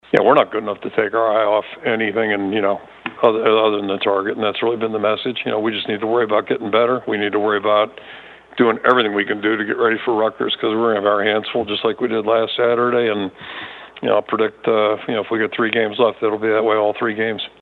That’s Iowa coach Kirk Ferentz who says every team needs to find ways to be successful and sometimes that changes during a season.